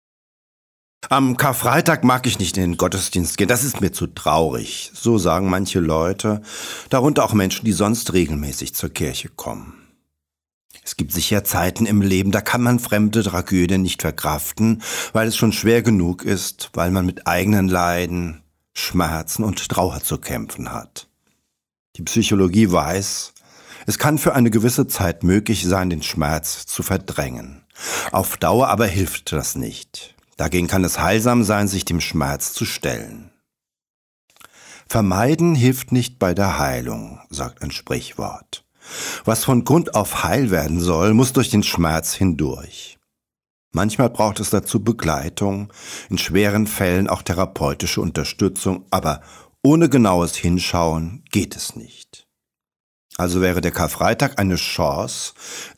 Karrfreitag-2025-Predigt-1.mp3